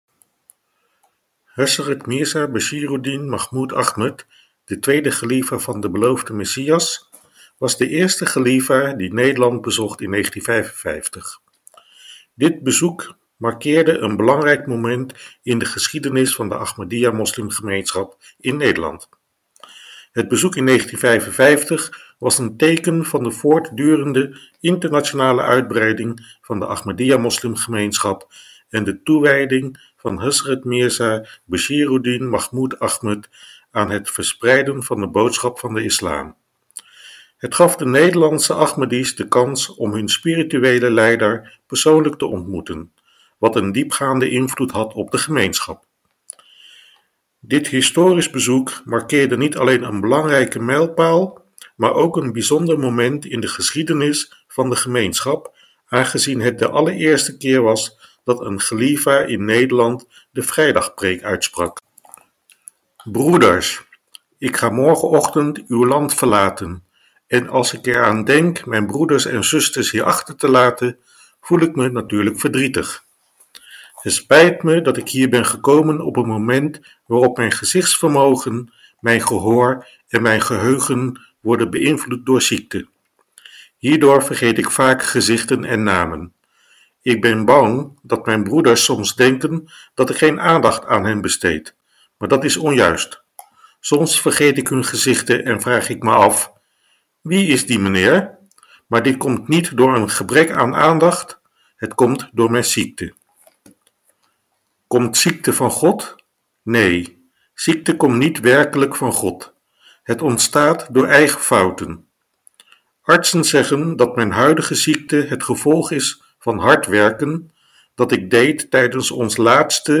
vrijdagpreek